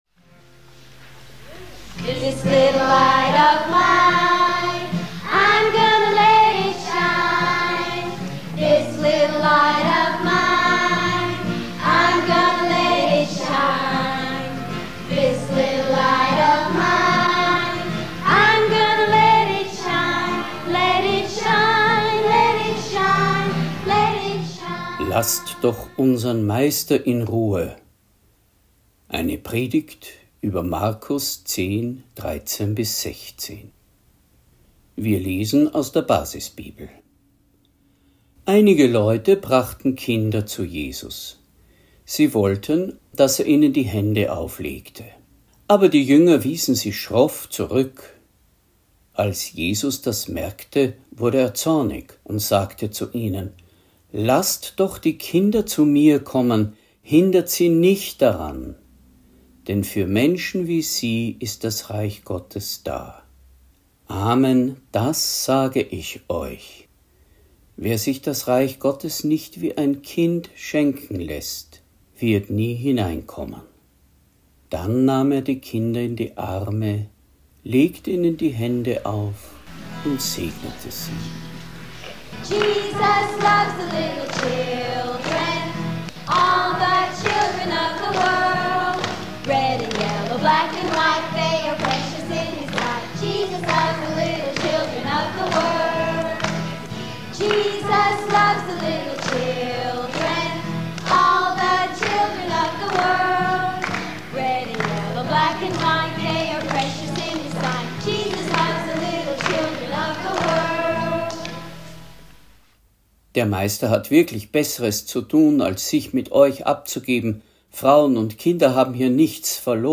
Predigt | NT02 Markus 10,13-16 Wenn ihr nicht werdet wie die Kinder – Glauben und Leben